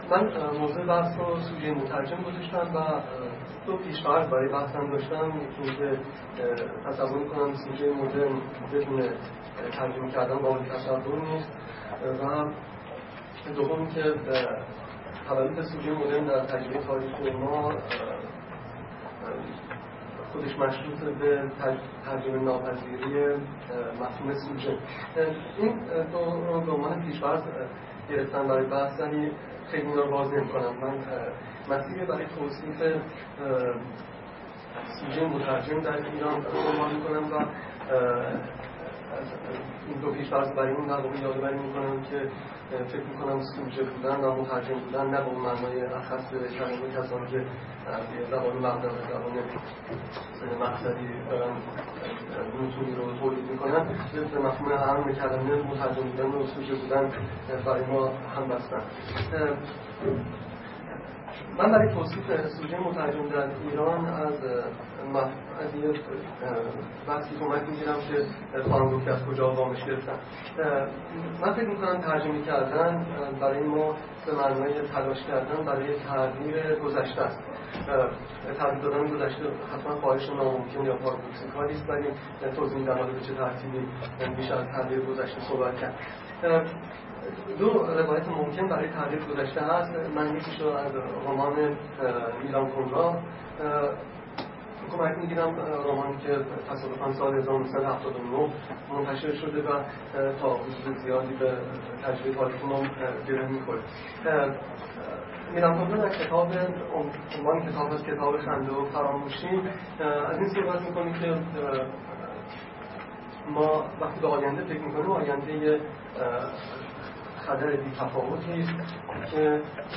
سخنرانی
در سمینار وضعیت ترجمه در ایران است که در مؤسسه مطالعات سیاسی اقتصادی پرسش برگزار شد.